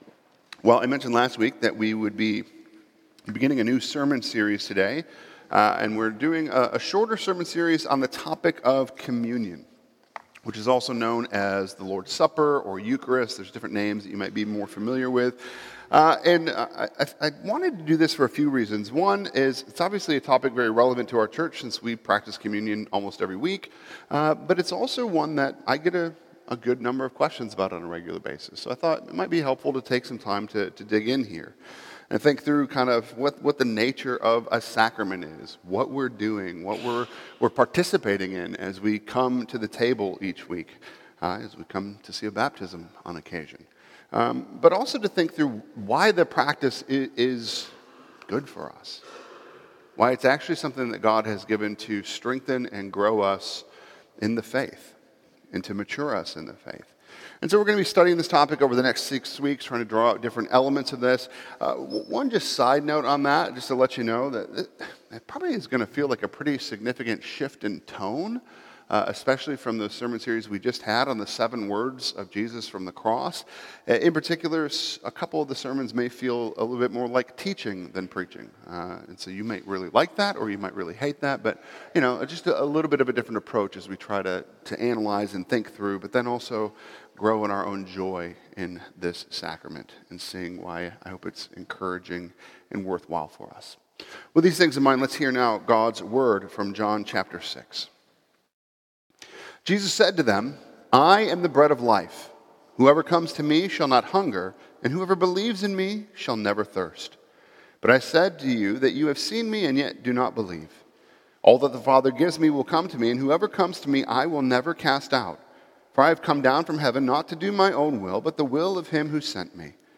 Weekly sermons from Grace & Peace PCA in Pittsburgh, PA.